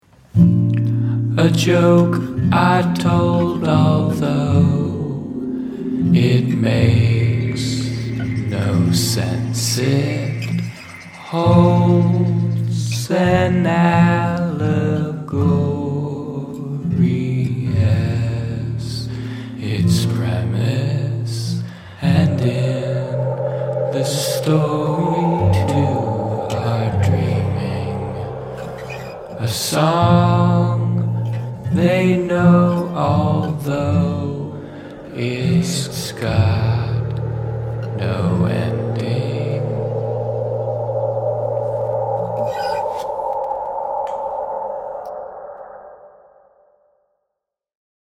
A: Dmaj7, E half dim, C# full diminished, Bm7, A, G, D
B: A, Bmin7, E
aaba